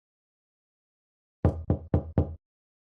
Free Foley sound effect: Door Knock.
Door Knock
019_door_knock.mp3